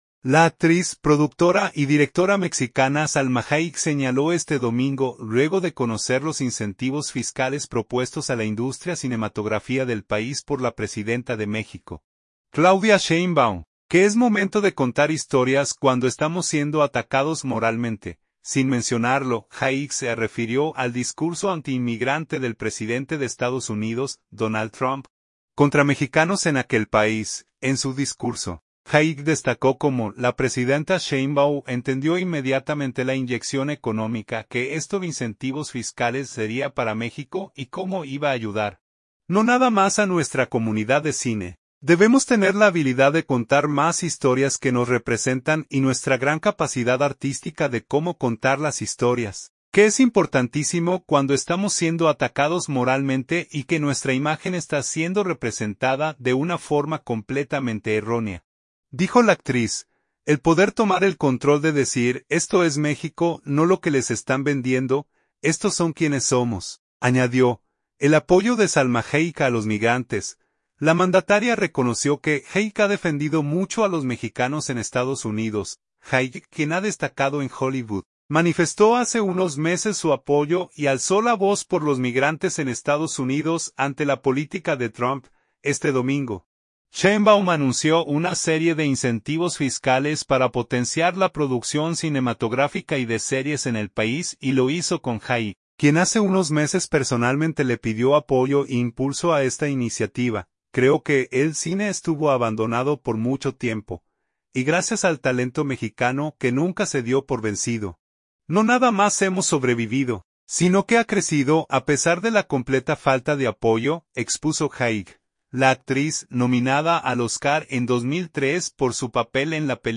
La actriz productora y directora mexicana Salma Hayek (i), y la presidenta de México, Claudia Sheinbaum, participan en una rueda de prensa este domingo, en el Palacio Nacional de la Ciudad de México.